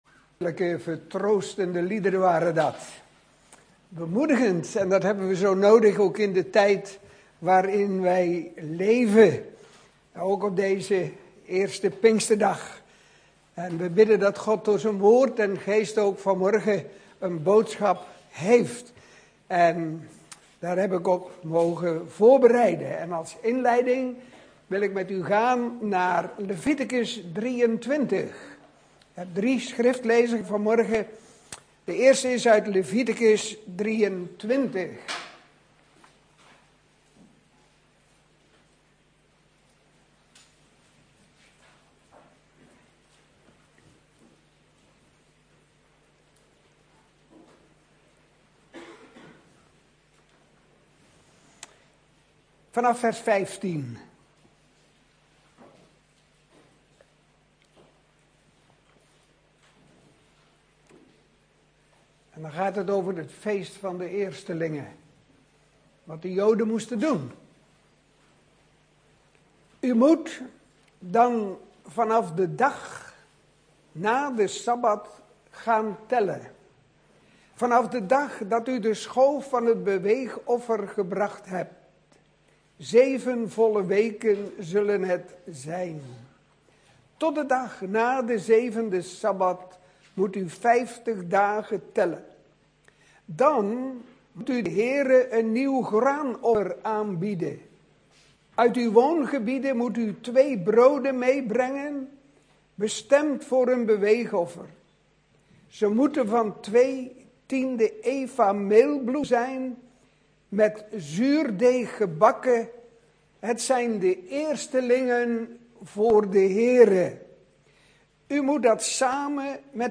In de preek aangehaalde bijbelteksten (Statenvertaling)